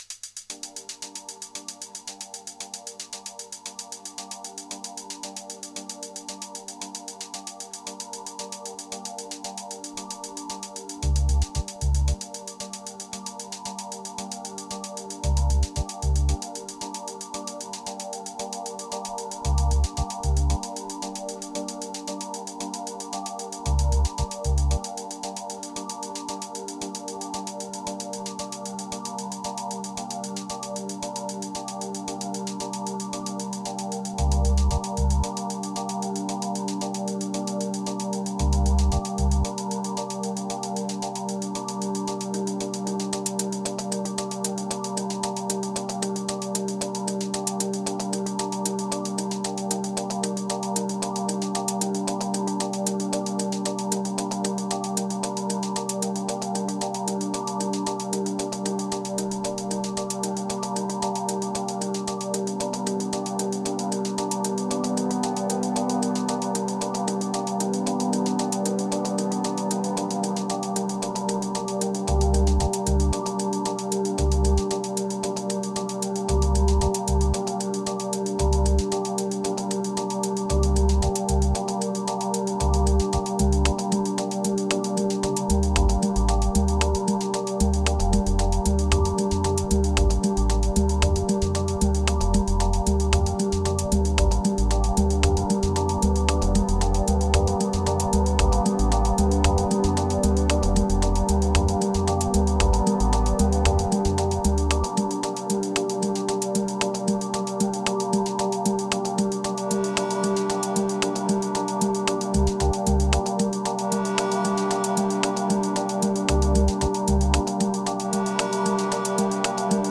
Prepare your subwoofer or your deep headz.